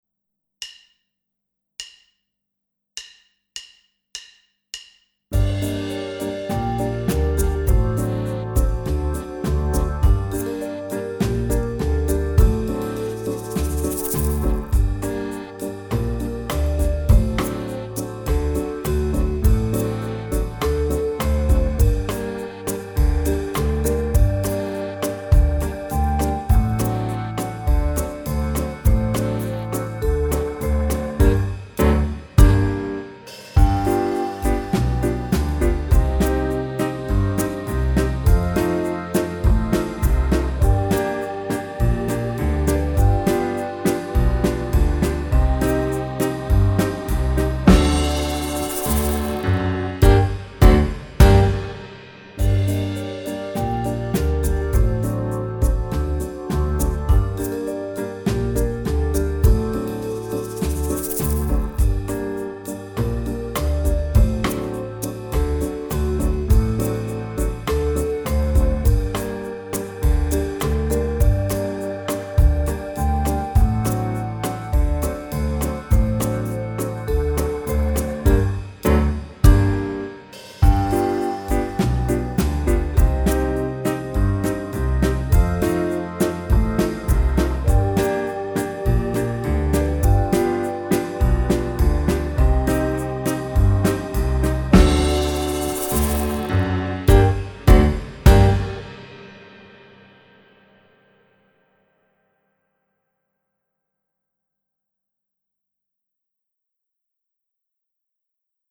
Don’t be put off by the tempo (we needed to play and count slowly today!)